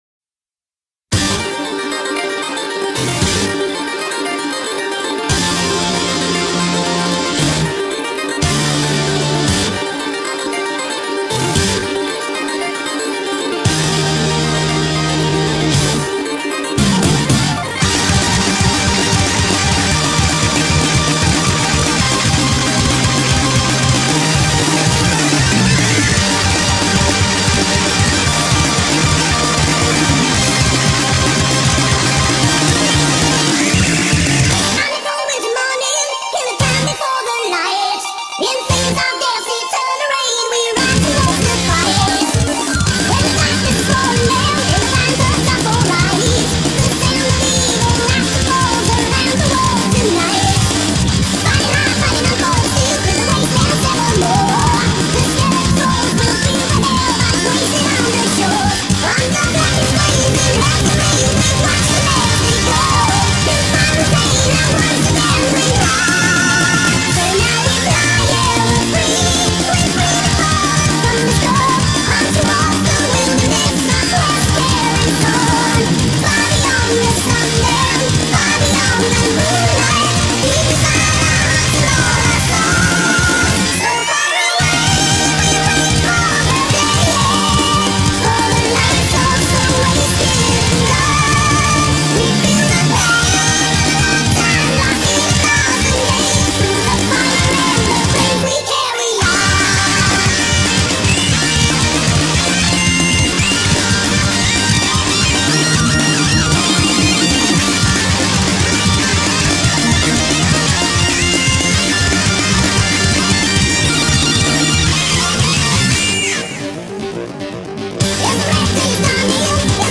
BPM115-230
Audio QualityCut From Video